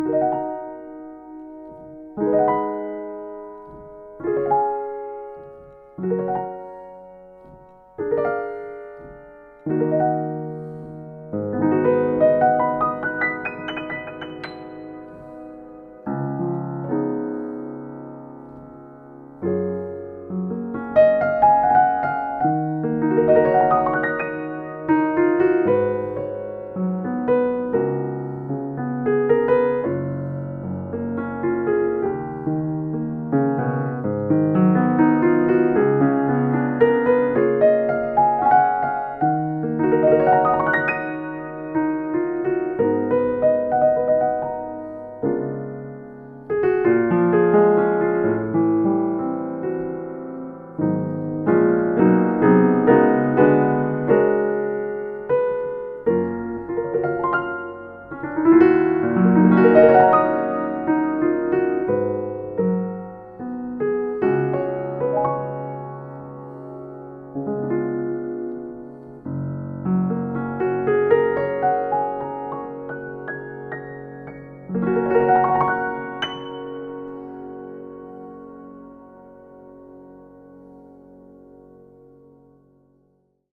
Kawai K2
Deze gebruikte Kawai K-2 is voorzien van het Millennium III mechaniek met ABS-carbon reageert op iedere nuance in de aanslag. De massieve zangbodem gemaakt van langzaam gegroeid vurenhout zorgt voor een duurzame, warme ronde klank.
Kawai-K2.mp3